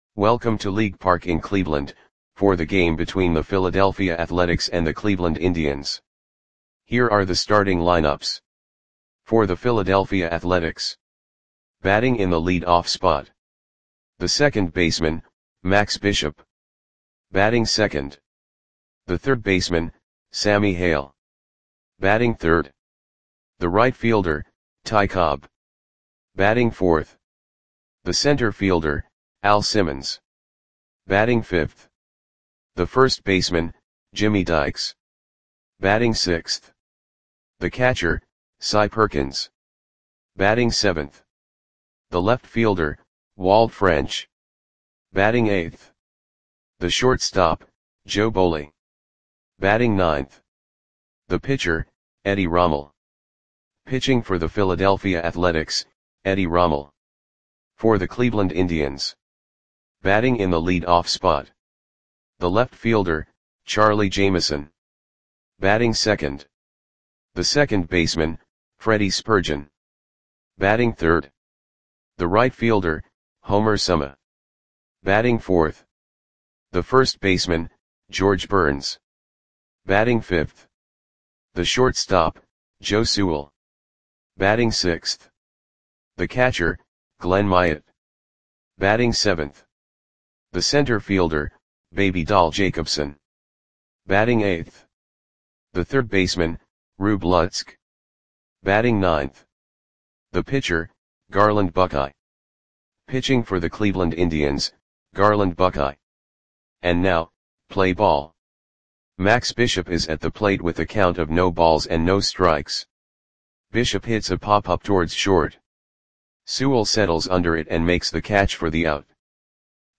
Audio Play-by-Play for Cleveland Indians on July 24, 1927